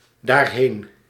Ääntäminen
France (Paris): IPA: [i.ɡʁɛk]